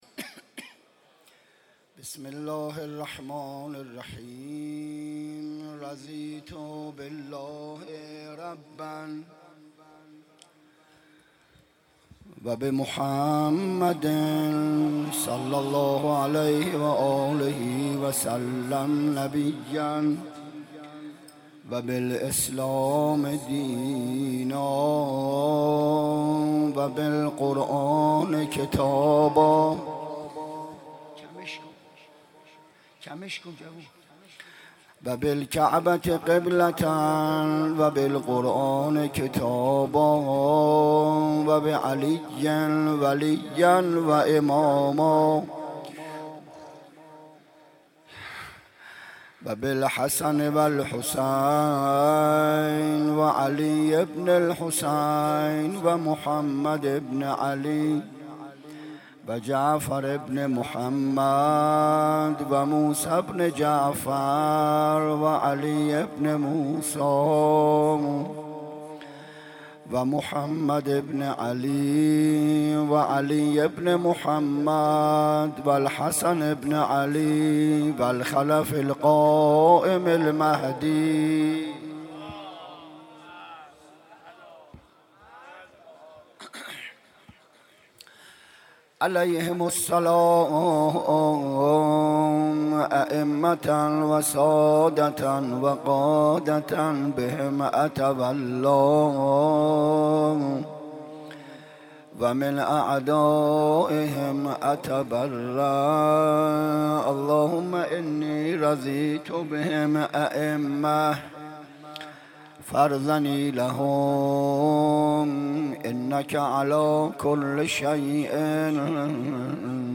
َشب هفتم محرم 95_سخنراني_بخش اول